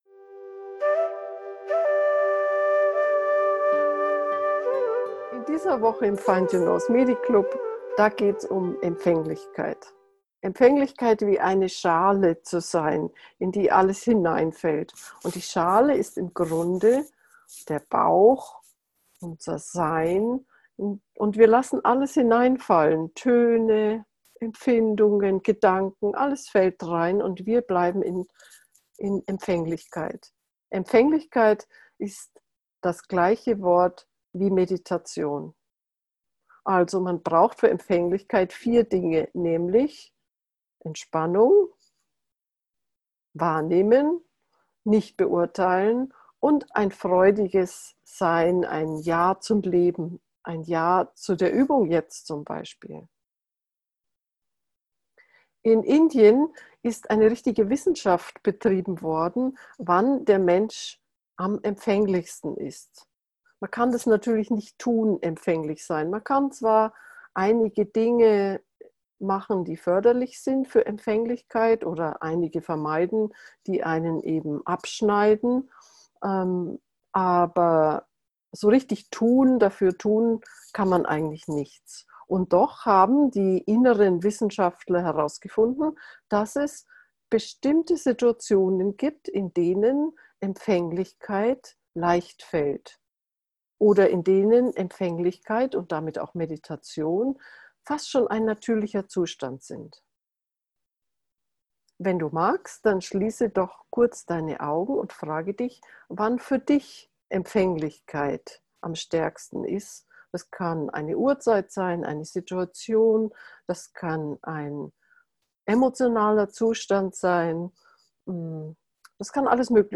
meditiere-wenn-empfaenglich-gefuehrte-meditation